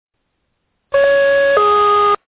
- Звуки, сигналы